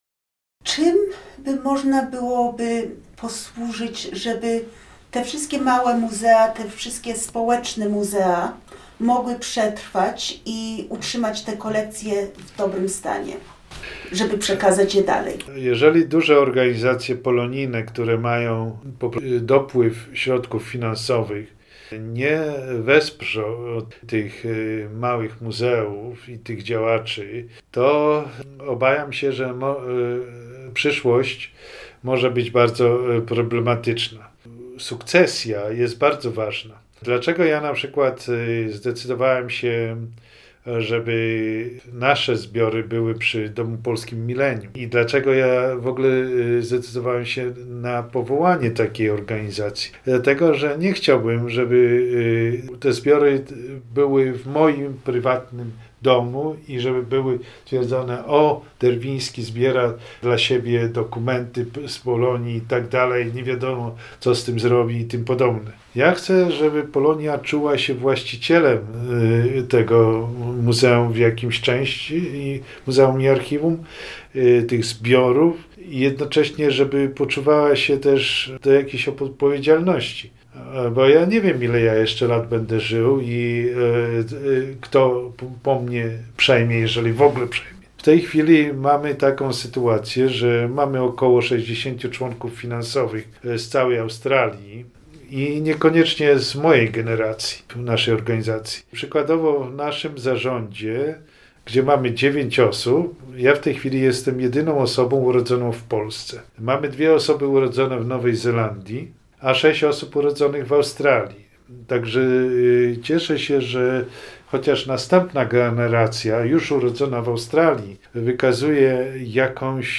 Fragment wywiadu z ubiegłego roku